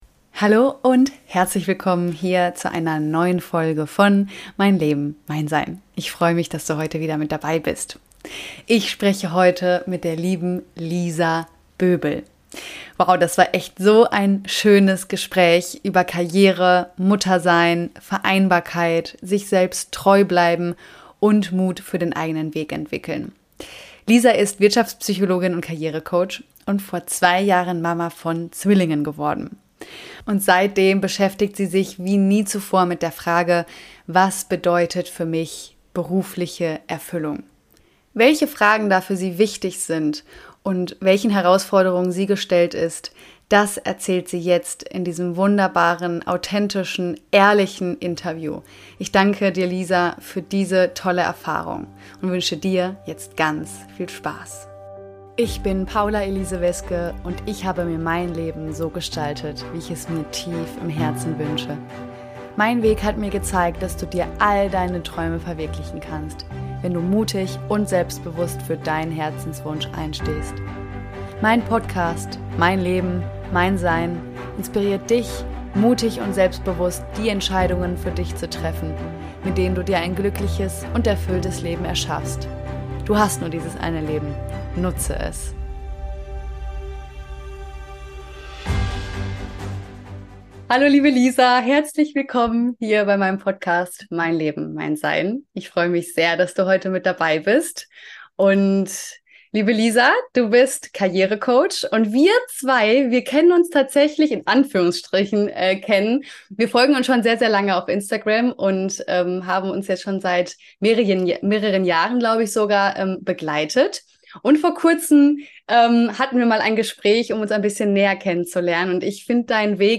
Interview Special
Wow, was für ein schönes Gespräch über Karriere, Muttersein, Vereinbarkeit, sich selbst treu bleiben und Mut für den eigenen Weg entwickeln.